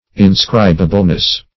Inscribableness \In*scrib"a*ble*ness\, n. Quality of being inscribable.